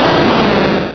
pokeemerald / sound / direct_sound_samples / cries / entei.aif